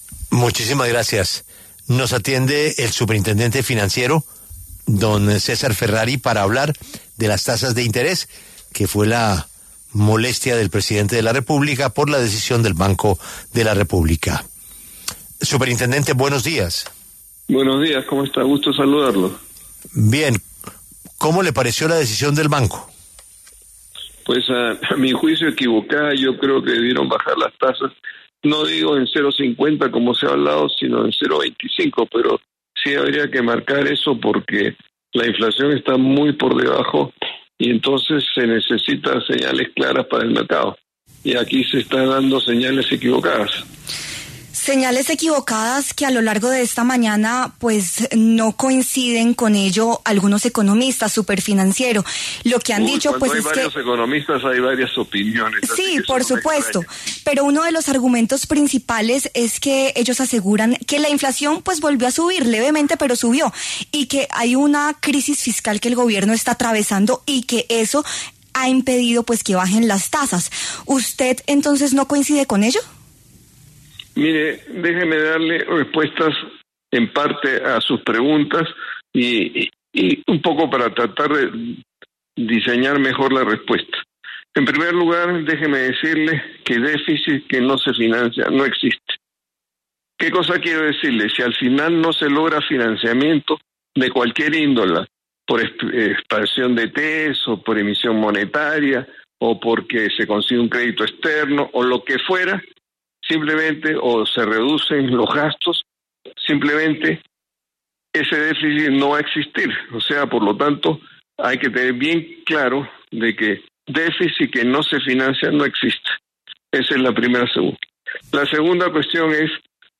El superintendente financiero, César Ferrari, afirmó en W Radio que la decisión del Emisor de mantener quietas las tasas fue equivocada.